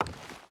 Wood Walk 4.ogg